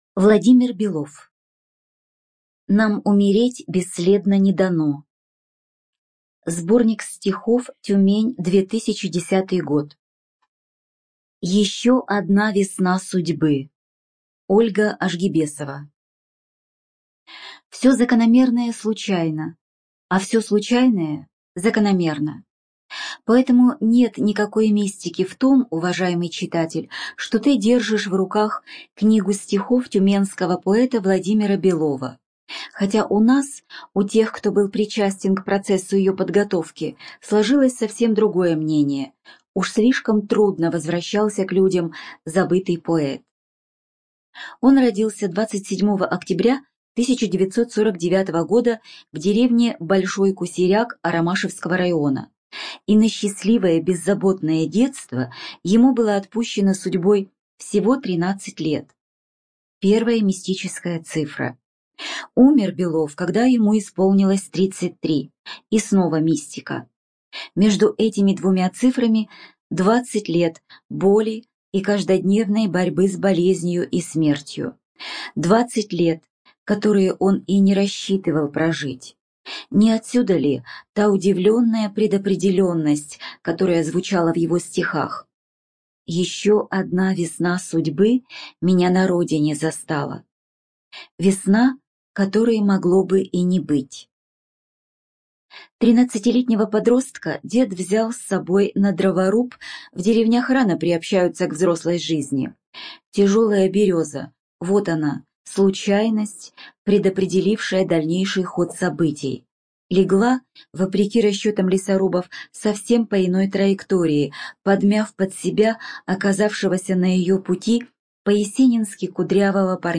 ЖанрПоэзия
Студия звукозаписиТюменская областная библиотека для слепых